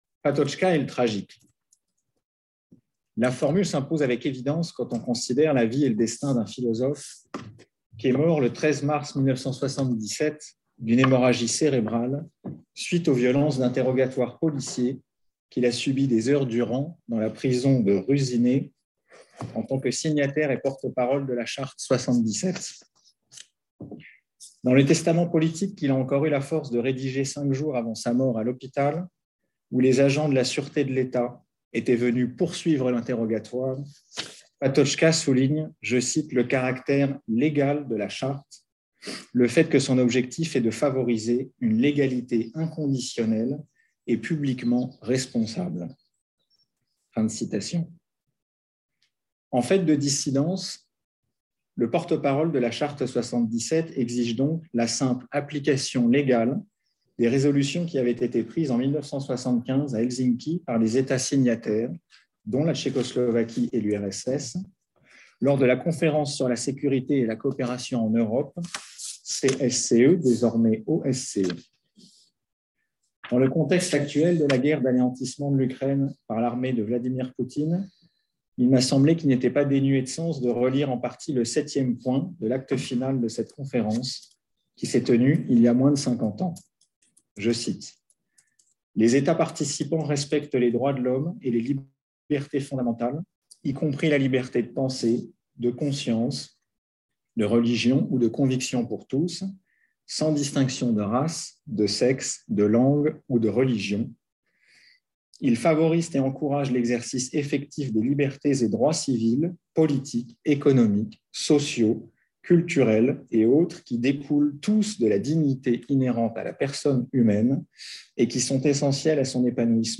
Cette conférence de clôture du colloque sur le philosophe tchèque Jan Patočka, qui s'est déroulé à la MRSH de Caen les 23 et 24 mars 2022, montre comment dans sa lecture d’Antigone Patočka se démarque de Hegel et en quoi cela lui permet de penser le sacrifice au-delà de tout volontarisme et au-delà de toute volonté de puissance. La pensée de Patočka propose une véritable phénoménologie du tragique en tant que structure de l’existence comme exposition à l’ « il y a ».